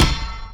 gearupshield.wav